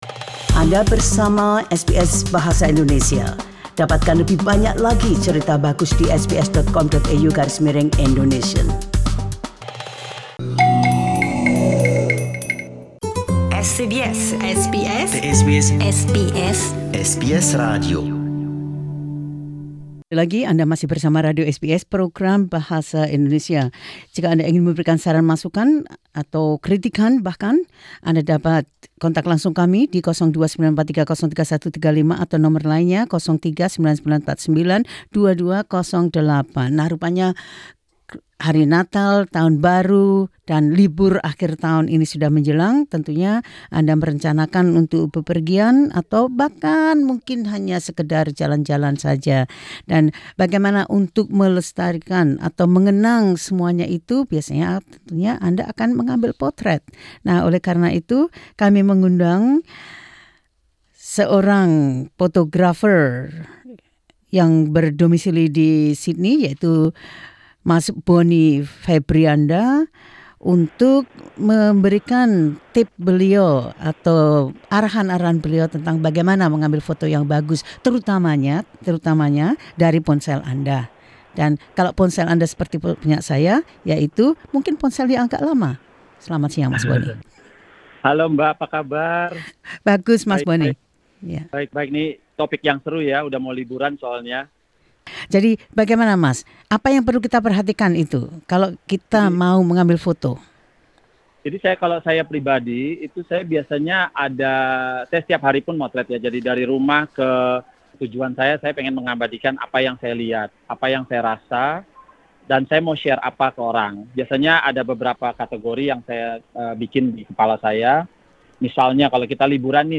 Dalam wawancara ini